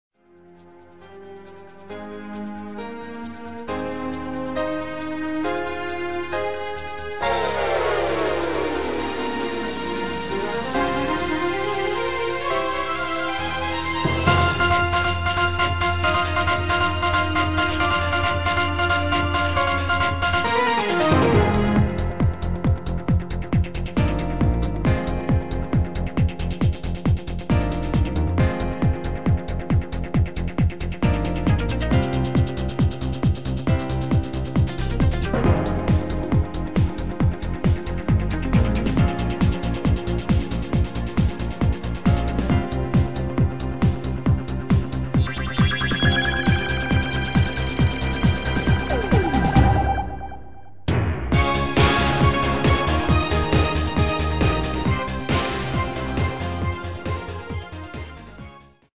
Demo/Koop midifile
Genre: Disco
- Vocal harmony tracks